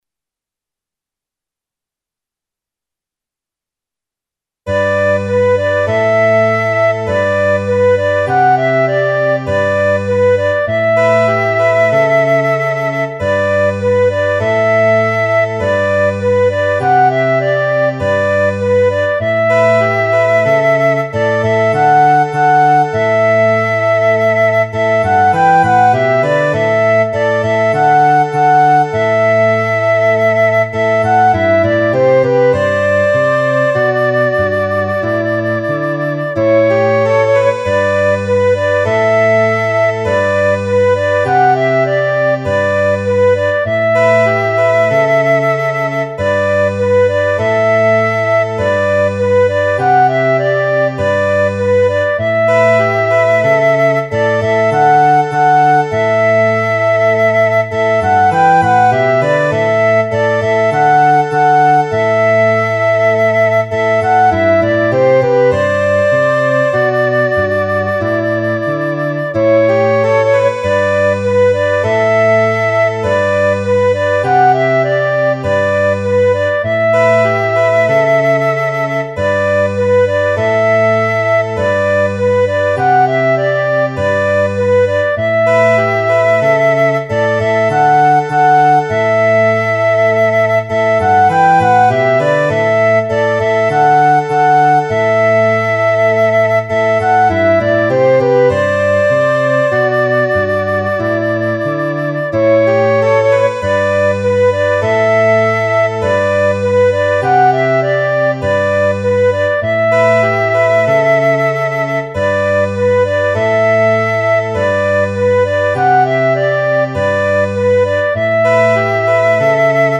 a quiet Psalm of gratitude.